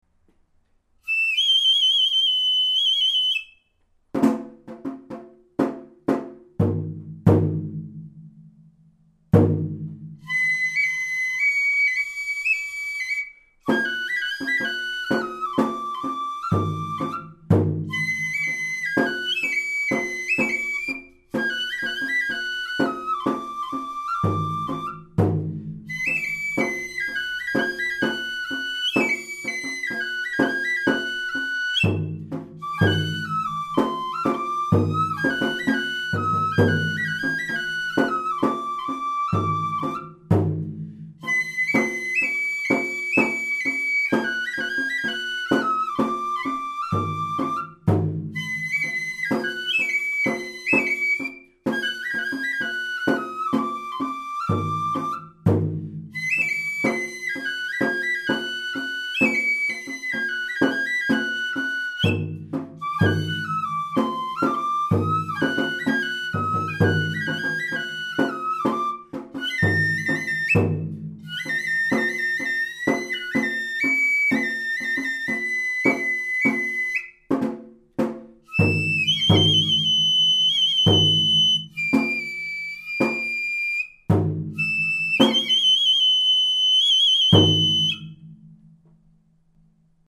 演奏音源：　稲荷　／
亀崎の稲荷は、曲の１番最初の部分の指使いが八-２-３と下の手から始まりますが、笠寺のお天道は５-５と上の手から始まります。
亀崎の稲荷は、笛は簡単ですが、１行目が通常よりも２拍多いので、太鼓の方は、出だしに注意が必要です。